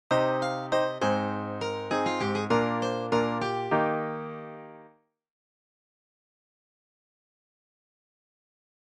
I’ve decided that I want to take the shape I’ve been using (start low, move up, then move down again), and invert it so that I start high, move low, then move high again). What that does is it helps to create a little climactic moment in my melody just where I’d probably want one: in the second half of my melody.